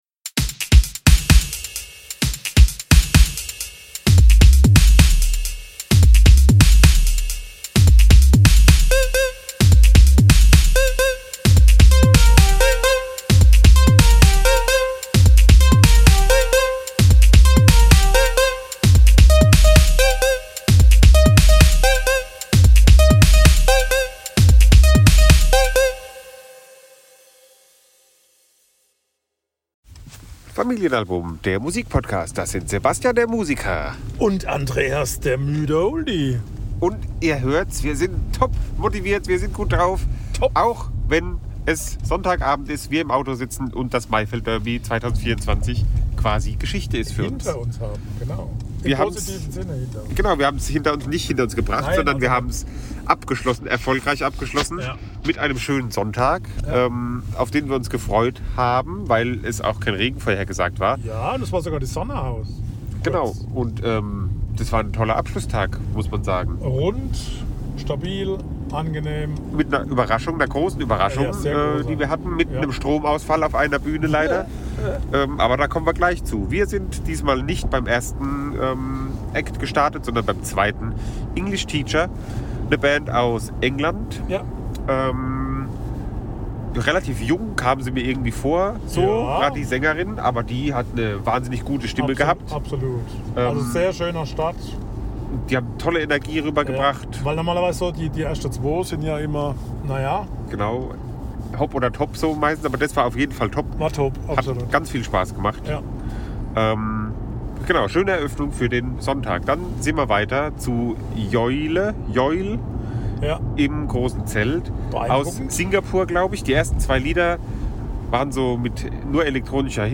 Das Maifeld Derby 2024 ist Geschichte! Auf der Rückfahrt lassen wir Tag 3 Revue passieren und ziehen ein Gesamtfazit.